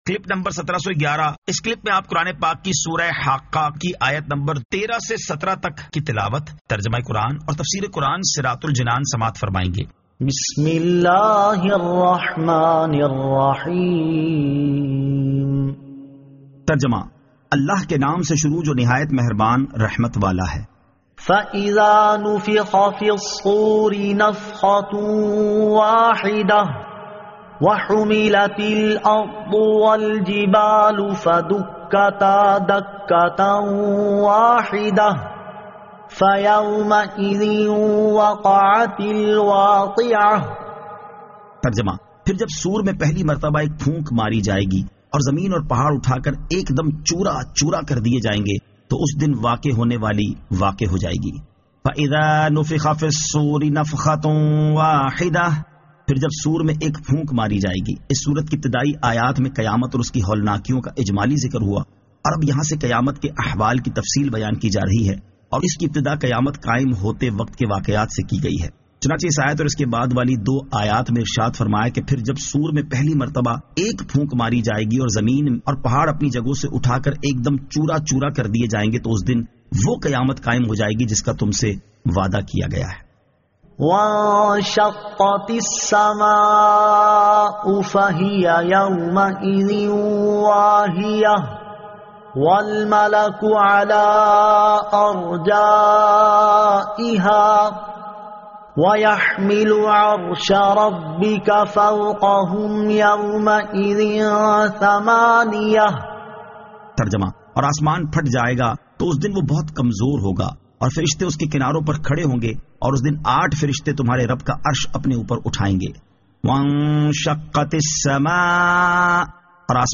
Surah Al-Haqqah 13 To 17 Tilawat , Tarjama , Tafseer